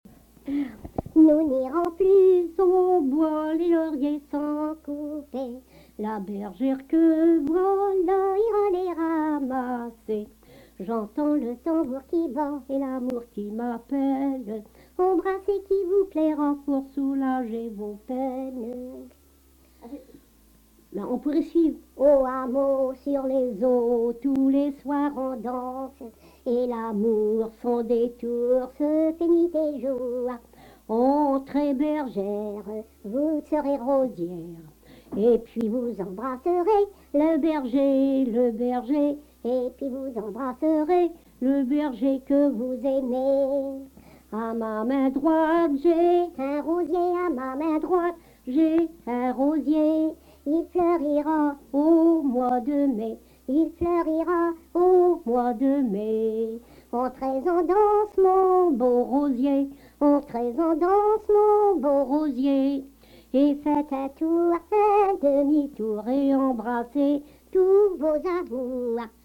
Chanson Item Type Metadata
Emplacement Miquelon